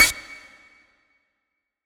TS - PERC (7).wav